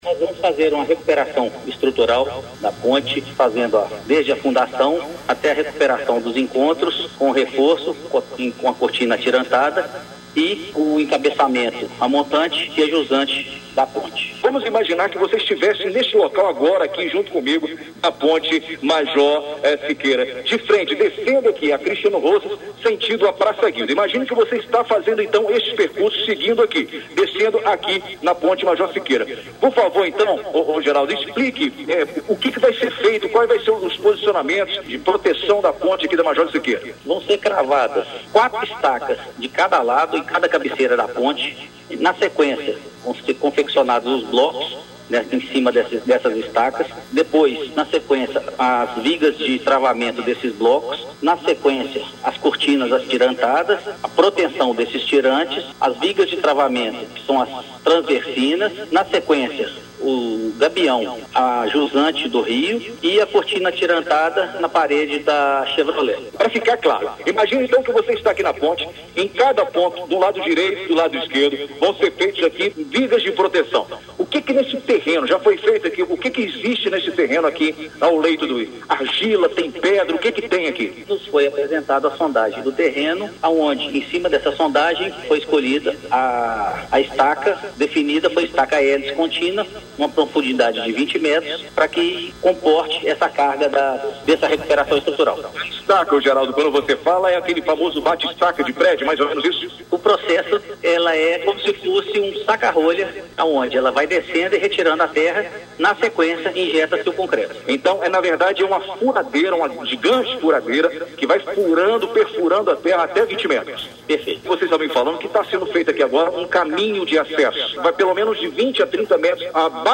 Entrevista exibida na Rádio Educadora AM/FM Ubá-MG